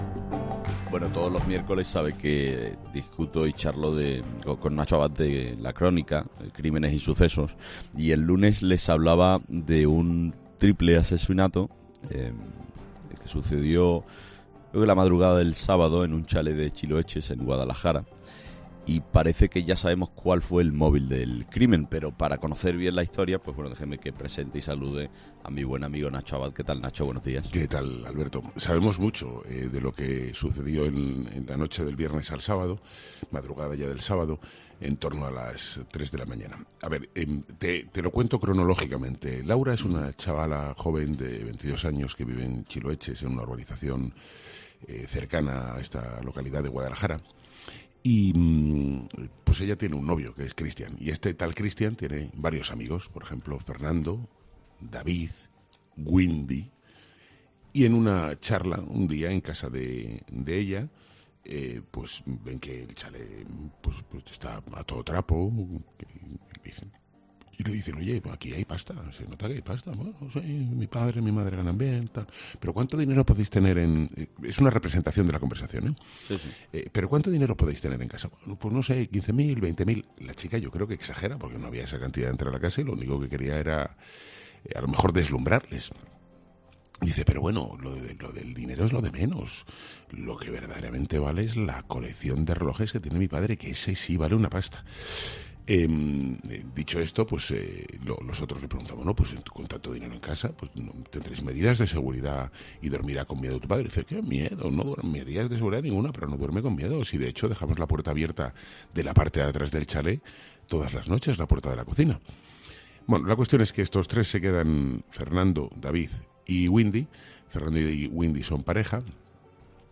El periodista especializado en sucesos, Nacho Abad, realiza un recorrido cronológico por Chiloeches
Todos los miércoles, en 'Herrera en COPE', hablamos con Nacho Abad de sucesos. En este caso, desgranamos el caso del triple asesinato en Chiloeches.